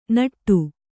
♪ naṭṭu